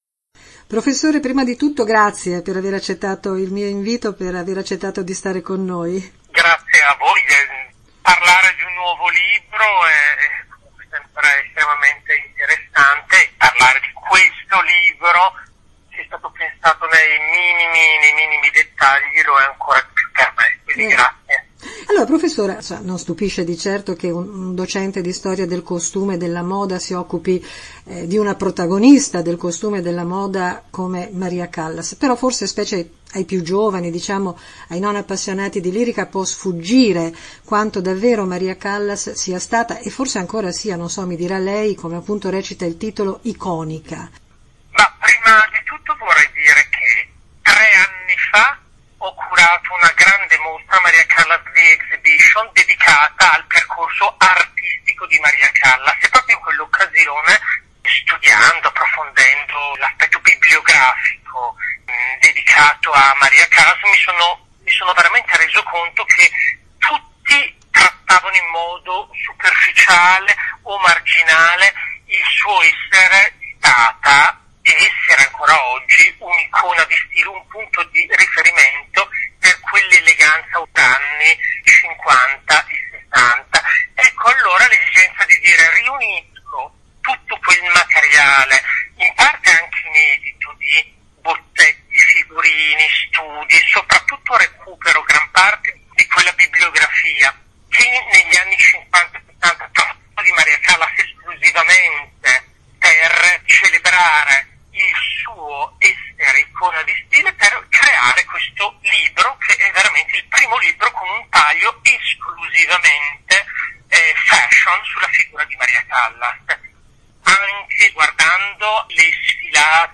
“Iconic Callas”: chiacchierata